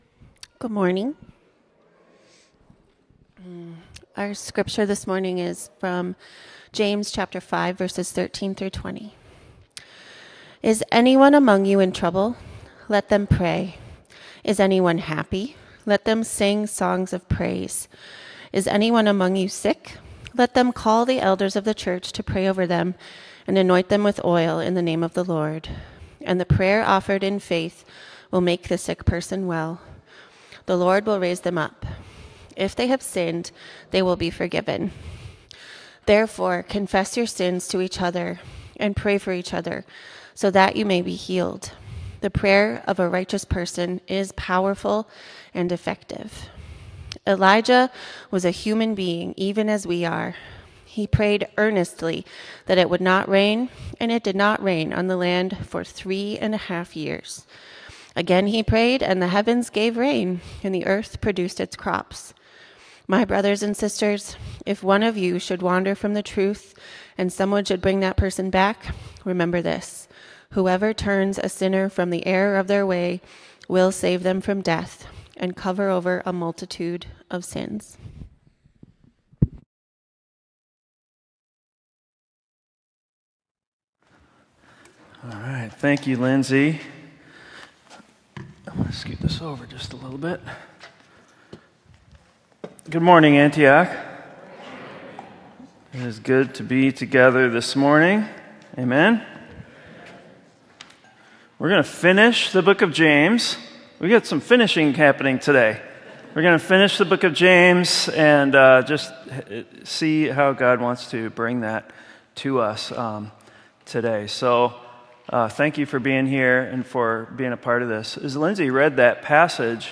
sermon-james-prayer-healing-and-never-giving-up.m4a